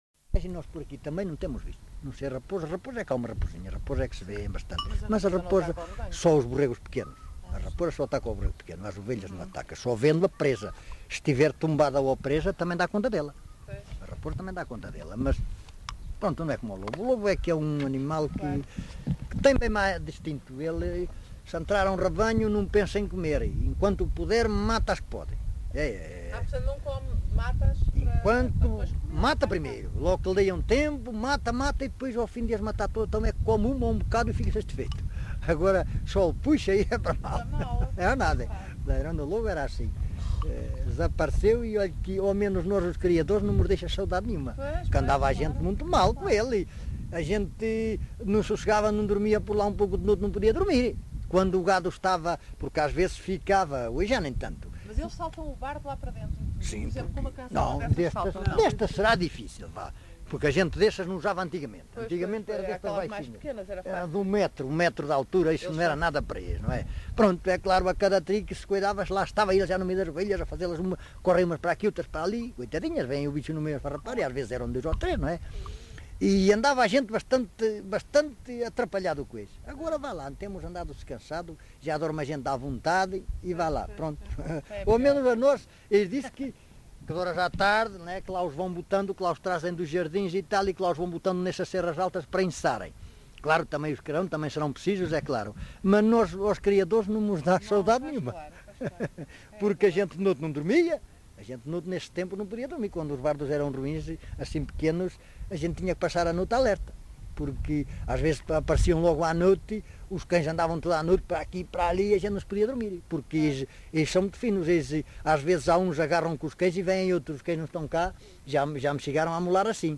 LocalidadeLarinho (Torre de Moncorvo, Bragança)